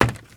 High Quality Footsteps
STEPS Wood, Creaky, Run 05.wav